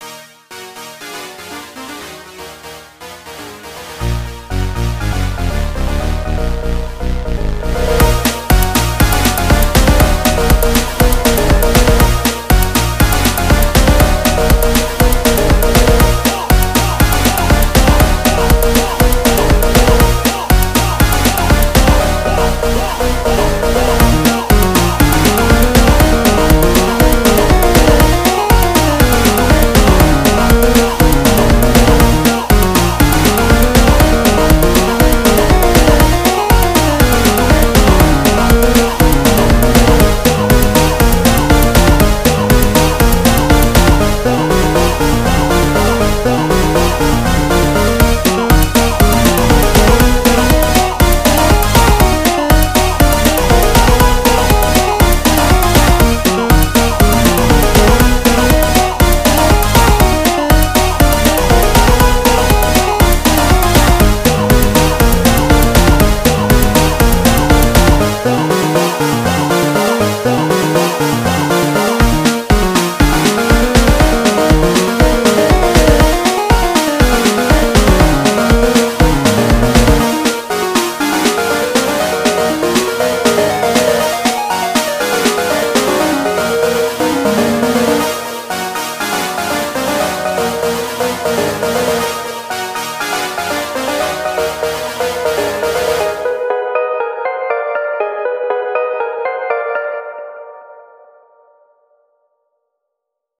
same thing but evil ;p